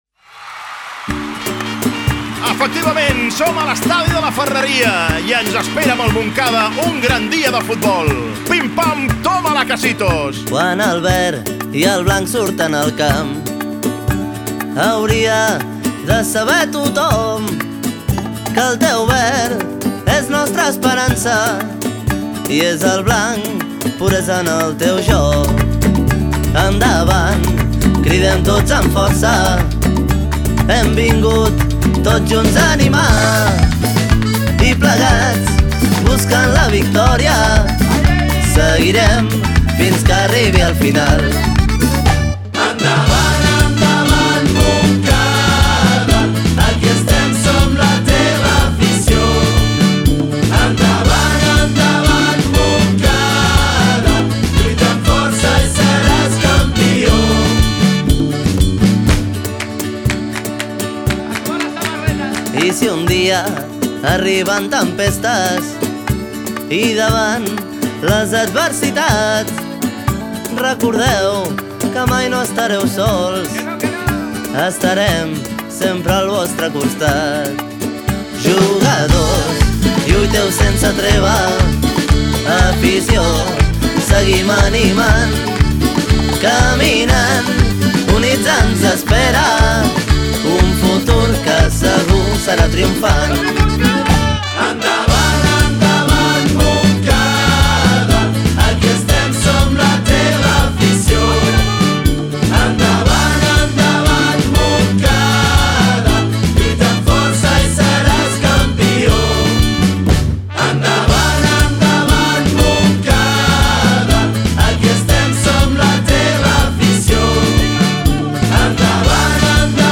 marca rumbera